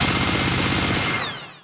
Minigun_BurstShot_2.ogg